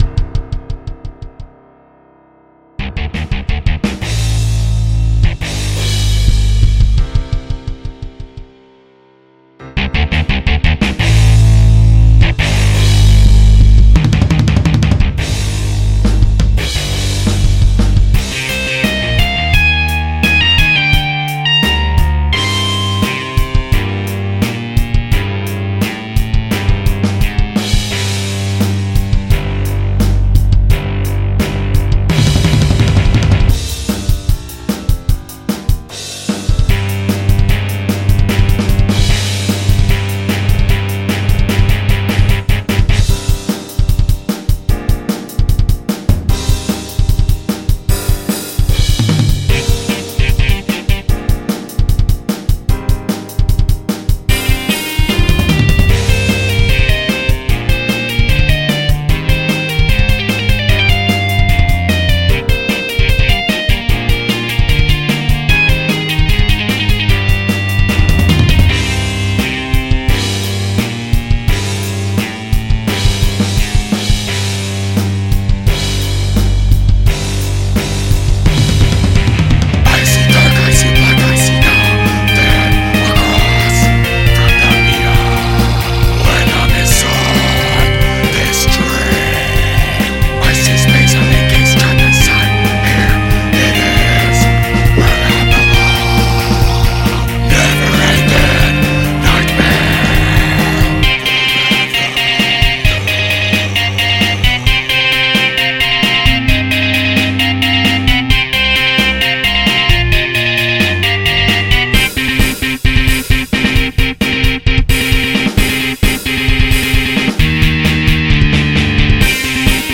- Jazz, Band, Pop, Rock - Young Composers Music Forum